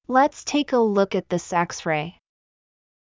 ﾚｯﾂ ﾃｲｸ ｱ ﾙｯｸ ｱｯﾄ ﾃﾞｨｽ ｴｯｸｽﾚｲ